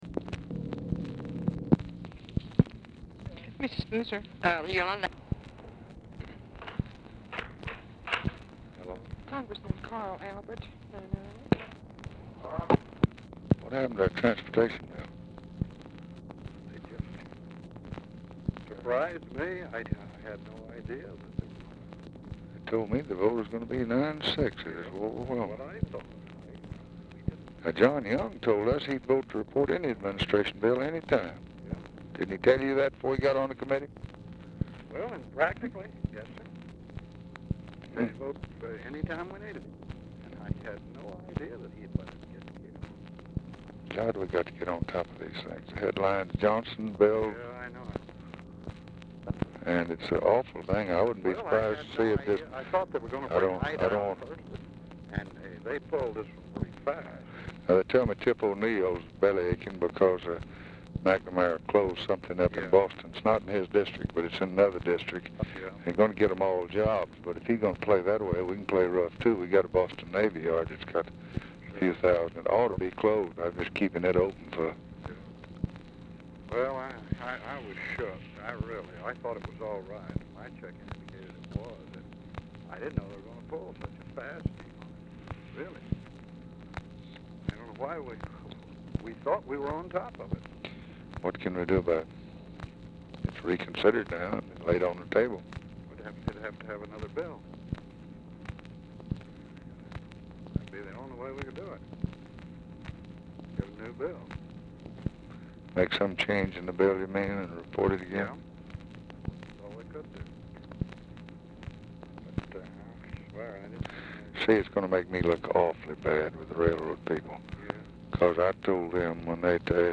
Format Dictation belt
Specific Item Type Telephone conversation Subject Business Congressional Relations Defense Federal Budget Legislation Press Relations Procurement And Disposal Transportation